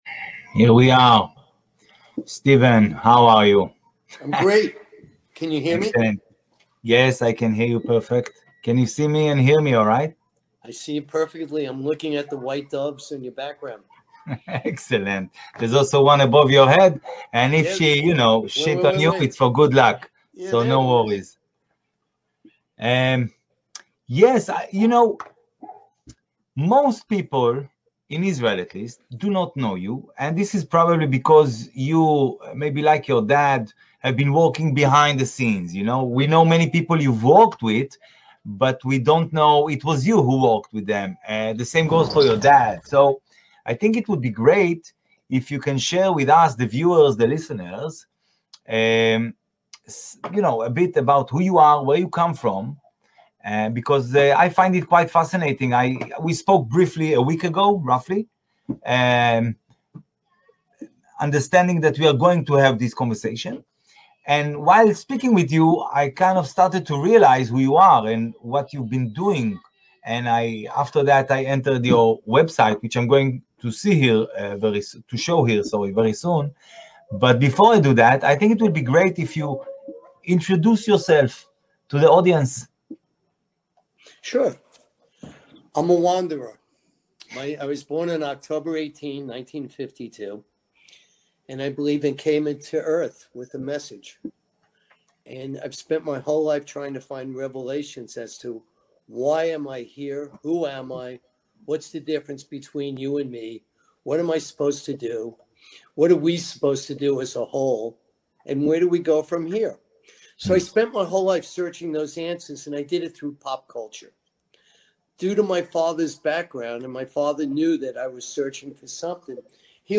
בראיון